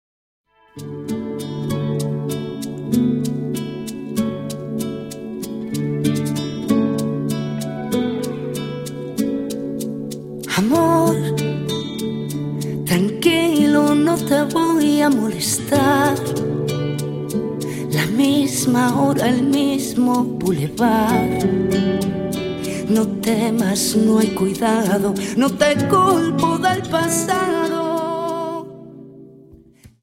Dance: Rumba 51